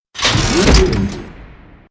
CHQ_FACT_door_unlock.ogg